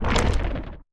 creaking_attack2.ogg